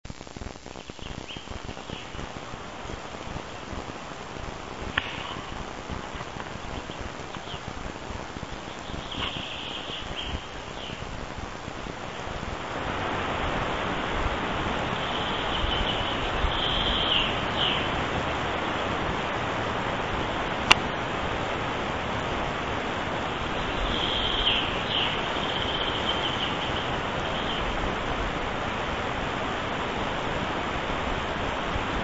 Ovšem poslech v místě, kde není široko daleko žádné elektrické vedení - to je zážitek.
Radioamatér značky PTáK
birds.mp3